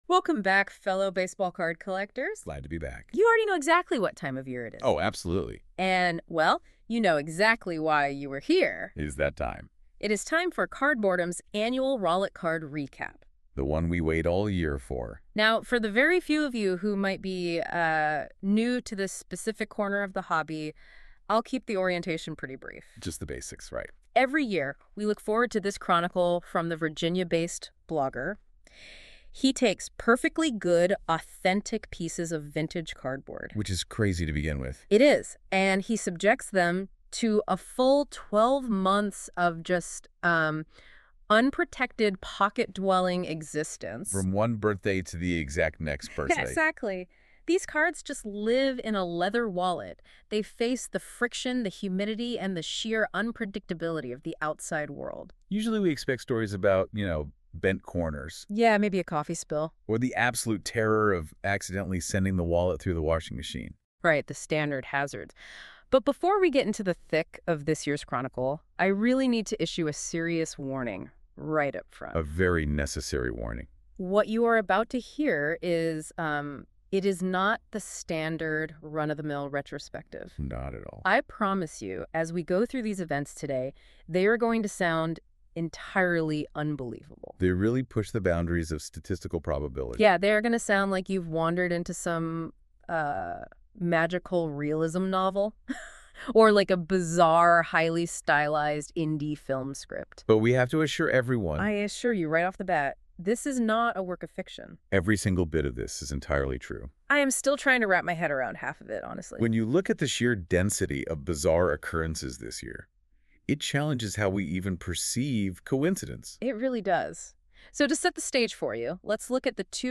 [Not a fan a longform textual cardboard destruction or want to listen in the car? Try this audio summary from some bots instead.]